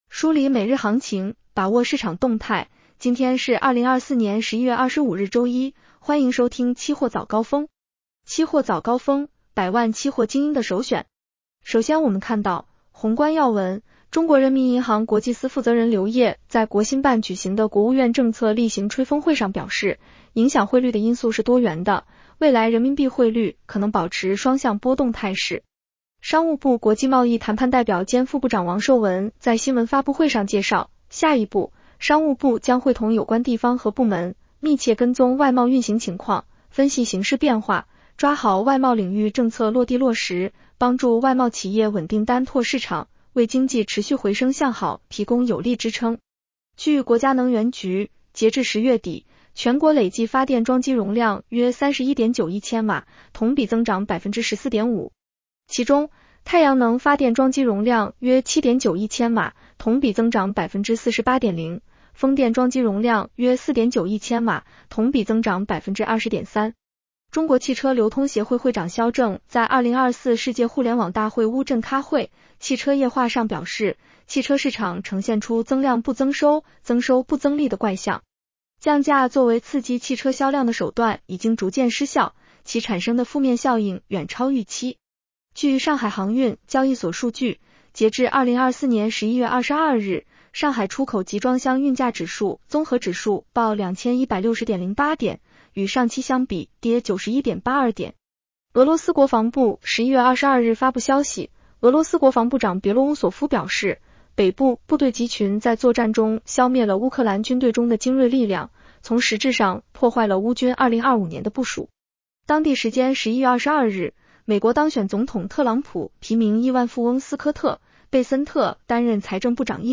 期货早高峰-音频版 女声普通话版 下载mp3 宏观要闻 1.